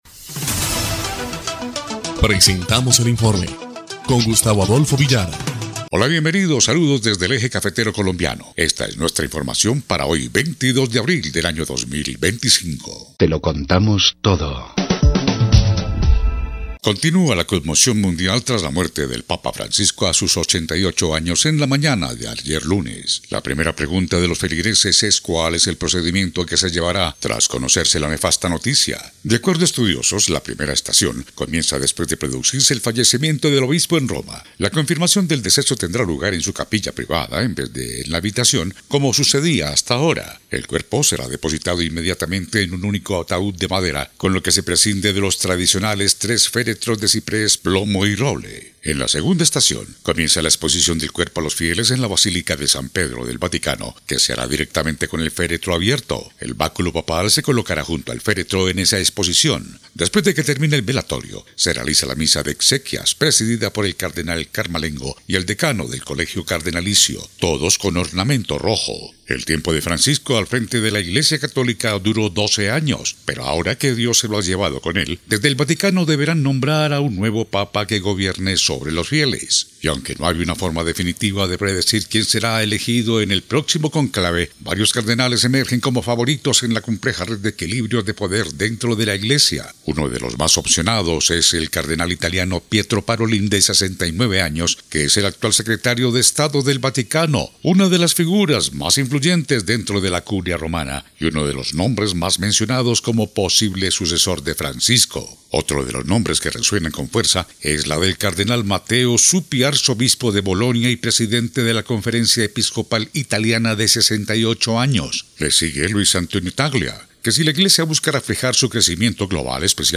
EL INFORME 2° Clip de Noticias del 22 de abril de 2025